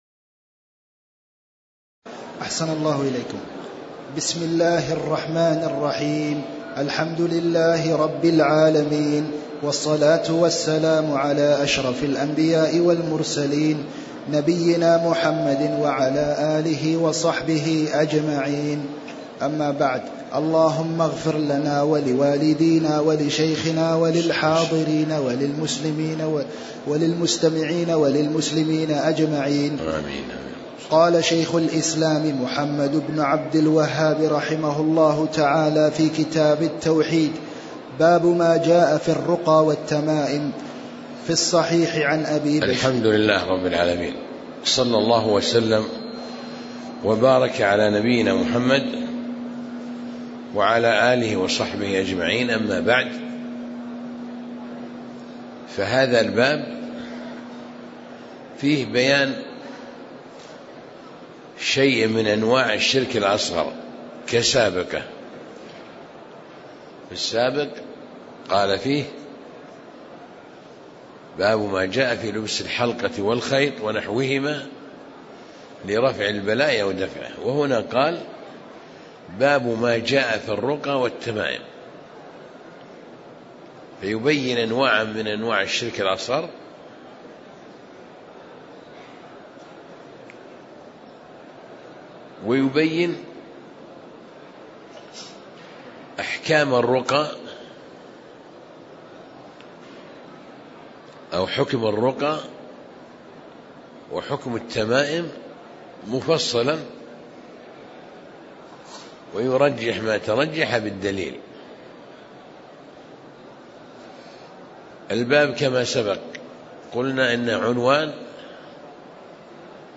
تاريخ النشر ٨ رجب ١٤٣٨ هـ المكان: المسجد النبوي الشيخ